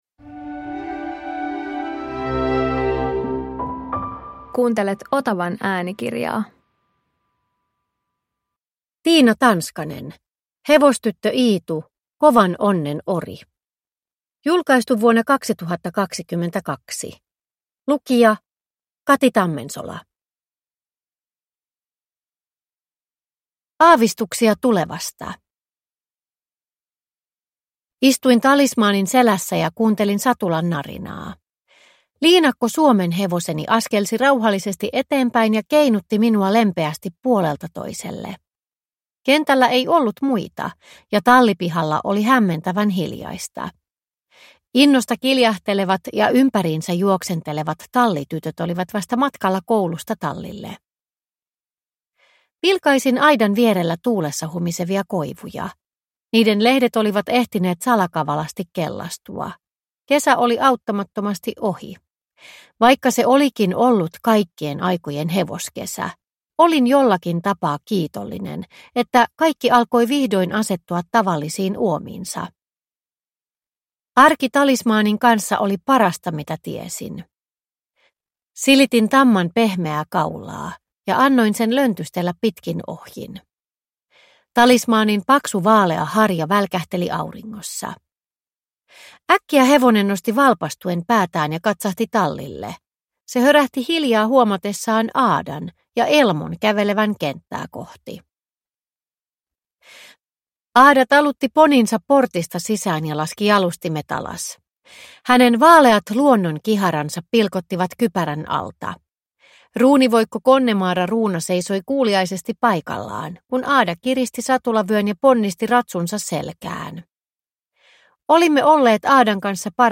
Kovan onnen ori – Ljudbok – Laddas ner